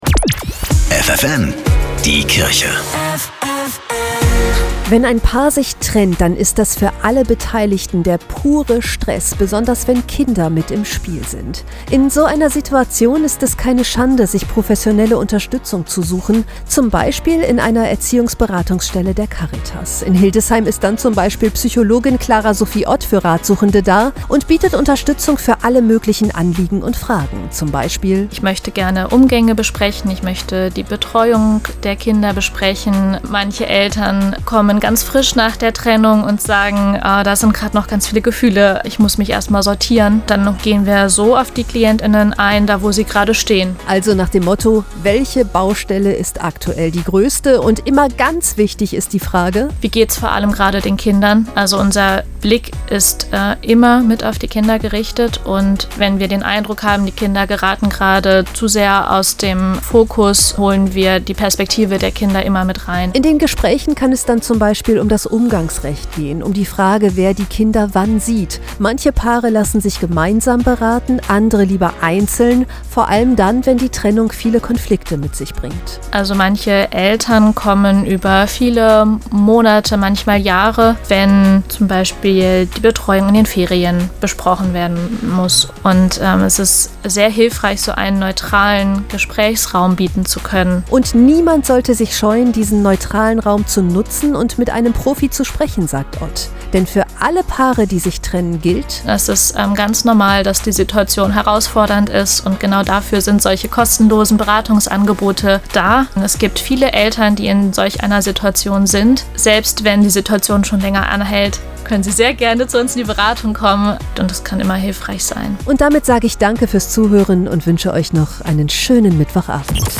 Radiobeiträge: